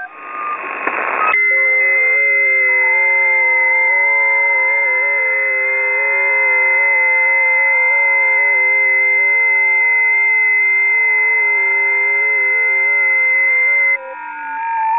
ft8_lib/tests/20m_busy/test_22.wav at 78b8f772c19dc6355fae728147ad09e8b76cdd29